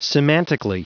Prononciation du mot semantically en anglais (fichier audio)
Prononciation du mot : semantically